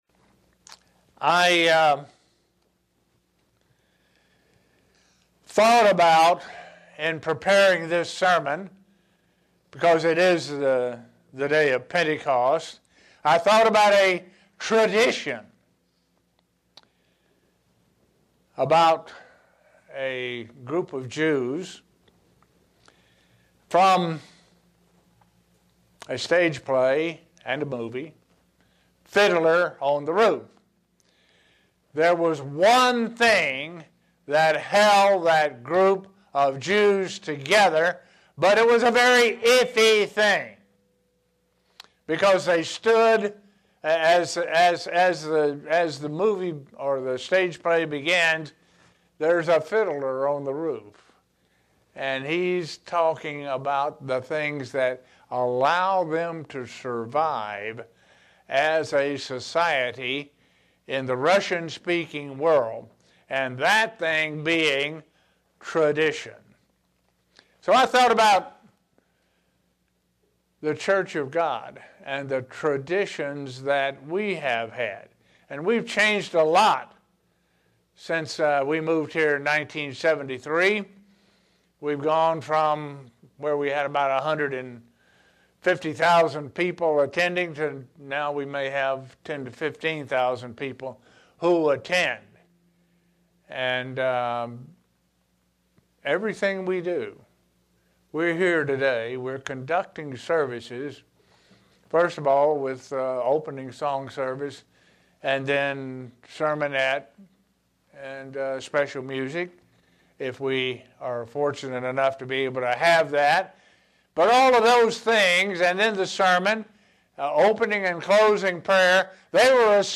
Given in Buffalo, NY Elmira, NY
Print To explain how four meanings of Pentecost are relivent to us in our spiritual development. sermon Studying the bible?